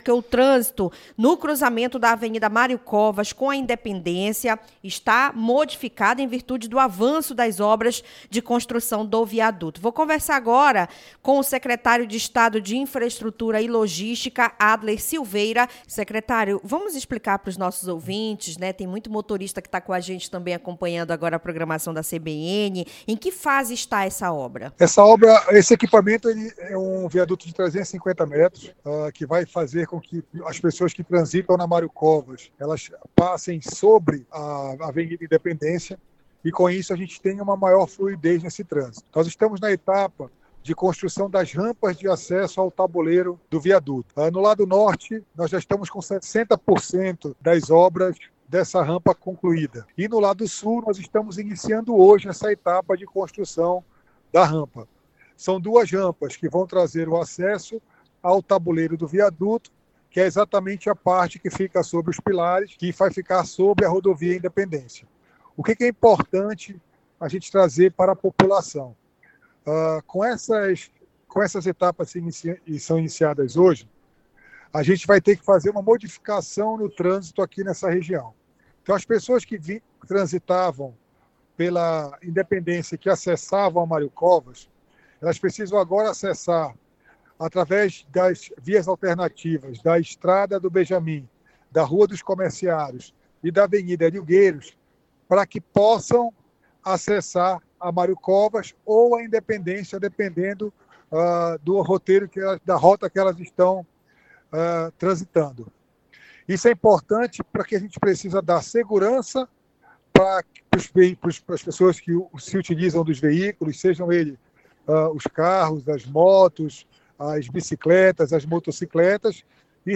0---ENTREVISTA-SECRETRIO-TRANSPORTE.mp3